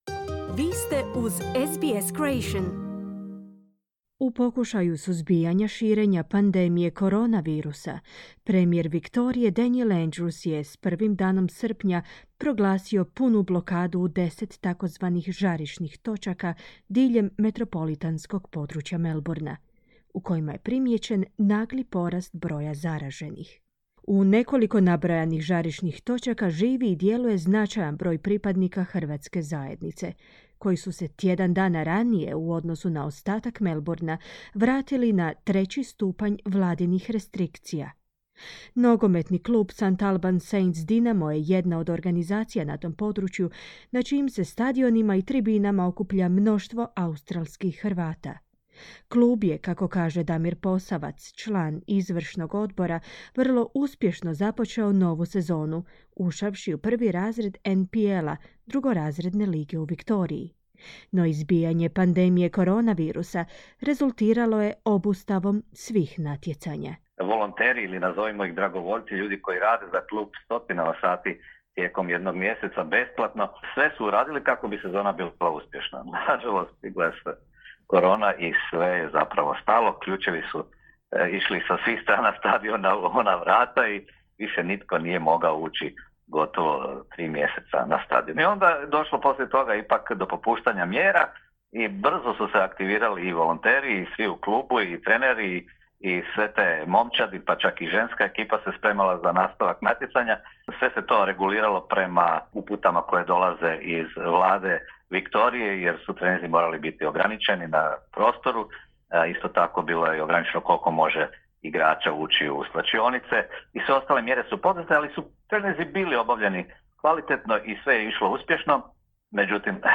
Od srijede, 8. srpnja, zbog naglog porasta broja zaraženih od COVIDa –19, šire područje Melbournea je ponovo stavljeno pod punu blokadu, natjeravši građane na ograničenje kretanja. Kako se opet priviknuti na restrikcije i što su najveći izazovi, organizacijski i psihološki pitali smo članove hrvatske zajednica iz kvartova na zapadu grada, koji su prvi vraćeni pod strože antipandemijske mjere.